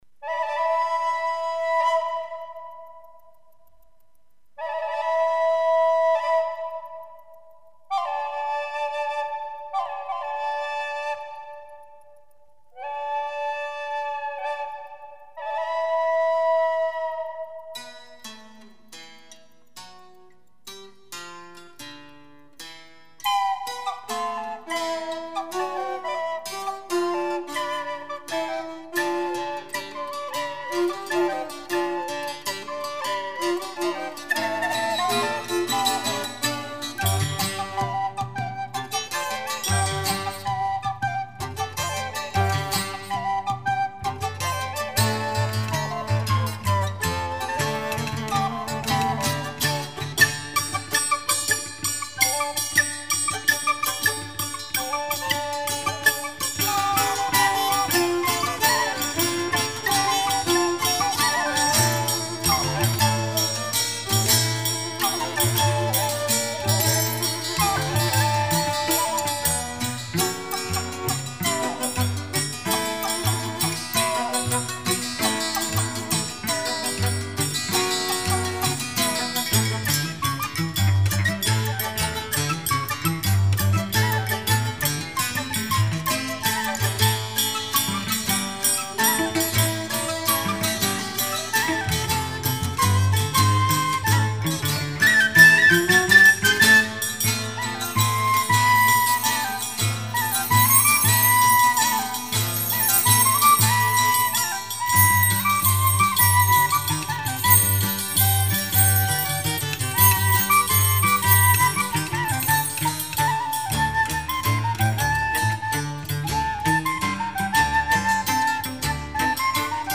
whistles